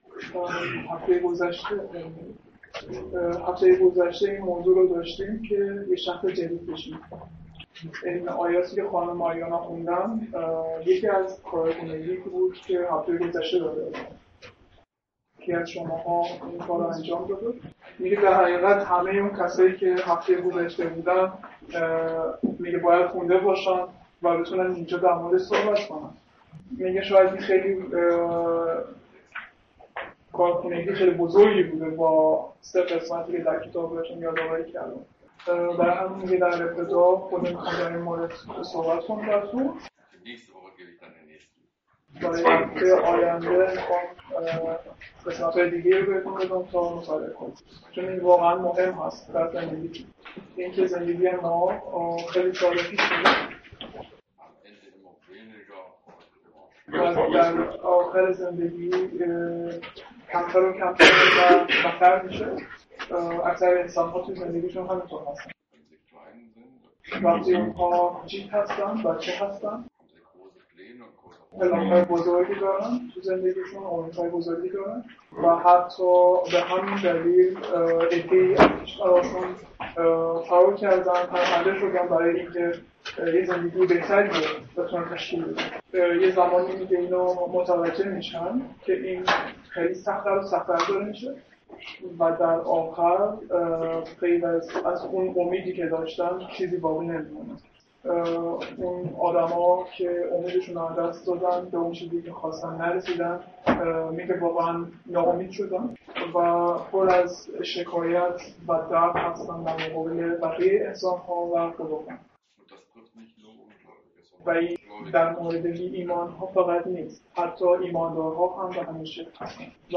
(16-7) ; دوم قرنتیان 4 - Predigt Europäische Missionsgemeinschaft
Übersetzung in Farsi.